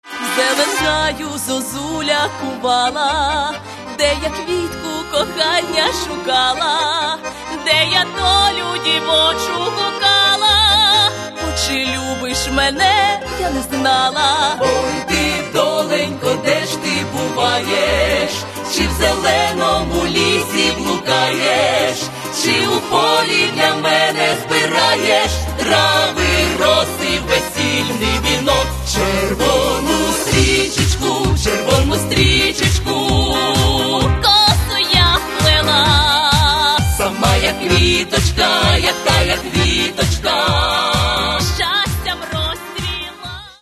Каталог -> Народна -> Ансамблі народної музики